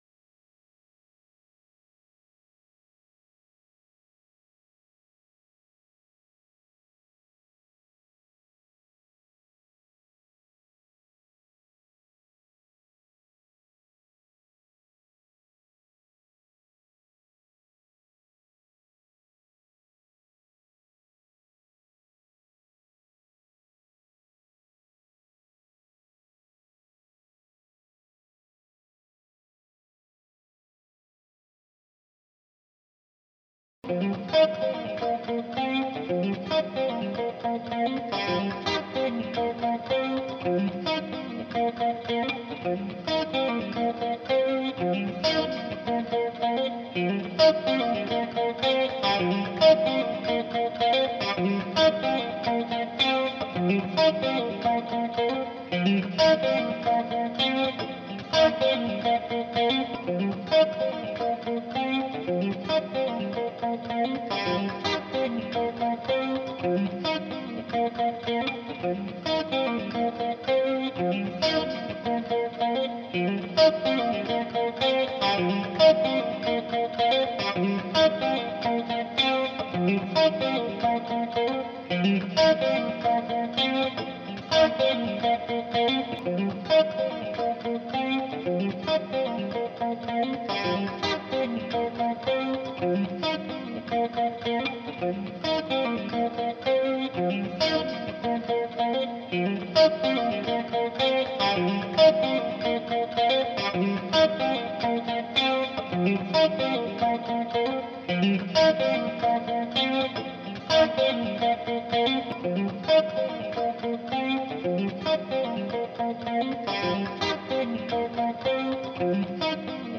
Hier noch eine zweite Version mit ein bisschen Wah zum Ende